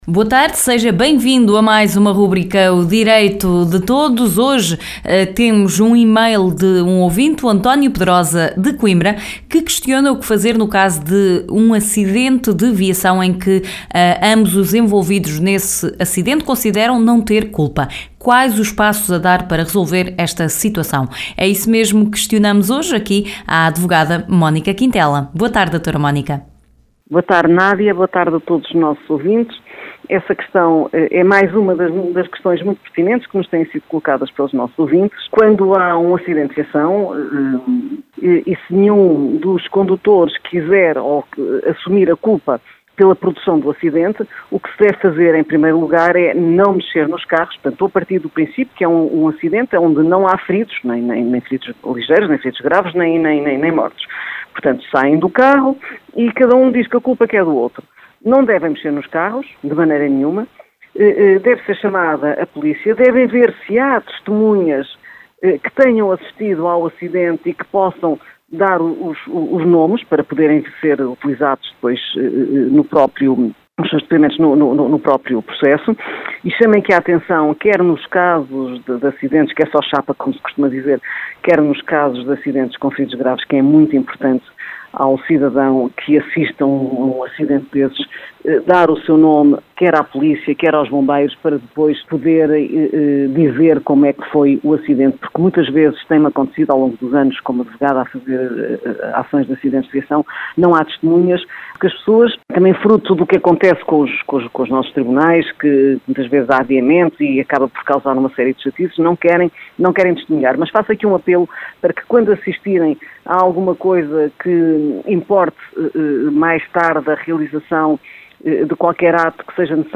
Na rubrica de hoje a advogada Mónica Quintela esclarece um ouvinte sobre os passos a seguir em caso de acidente de viação.